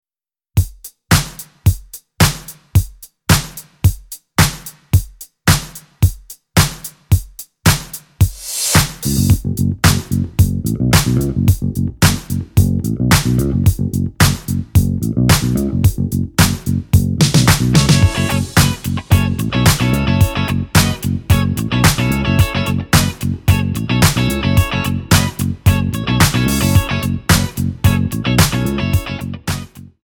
--> MP3 Demo abspielen...
Tonart:F#m ohne Chor